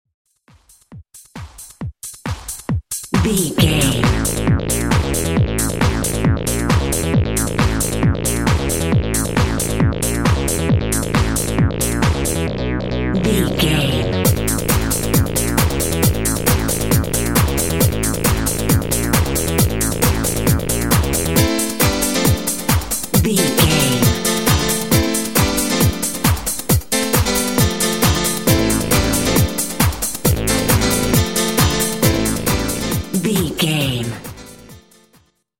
Aeolian/Minor
groovy
futuristic
industrial
uplifting
drum machine
synthesiser
house
techno
electro house
synth lead
synth bass